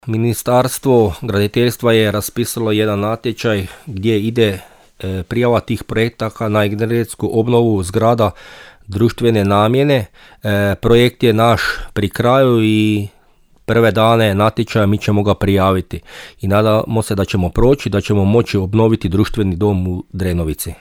-rekao je u emisiji Susjedne općine načelnik Remetović.